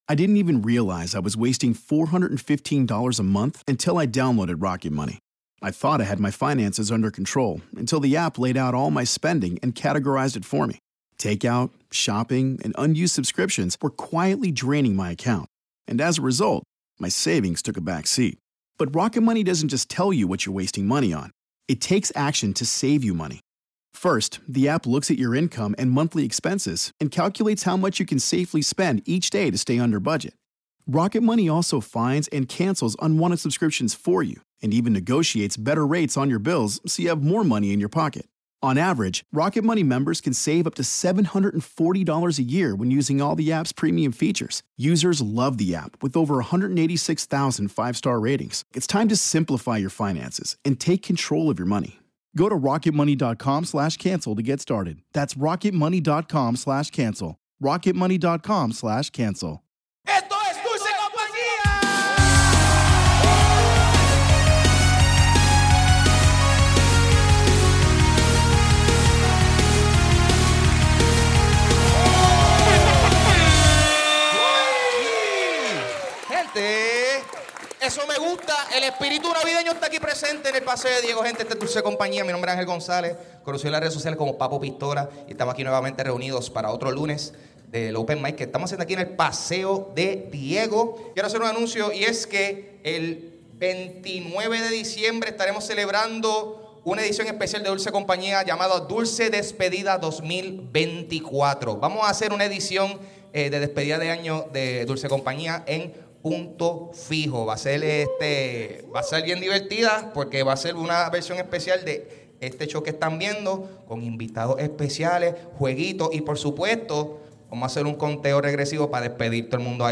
Descubrimos el gran talento de imitación que tiene uno de nuestros comediantes.